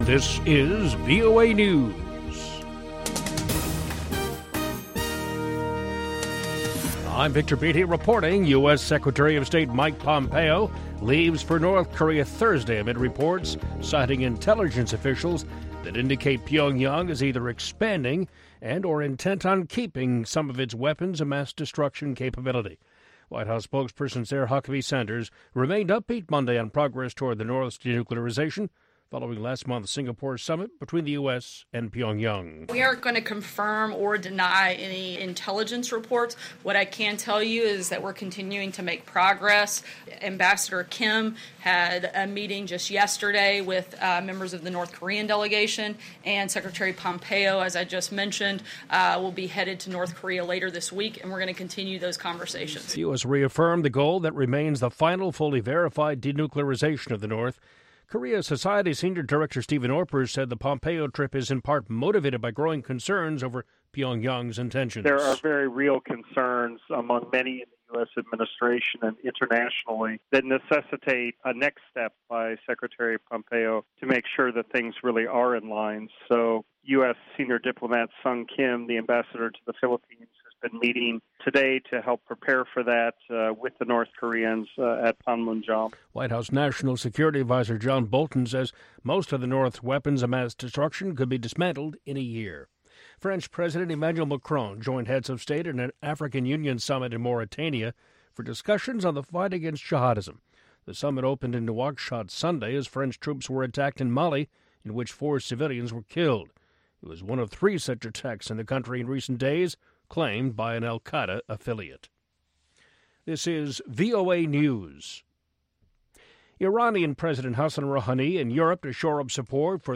contemporary African music and conversation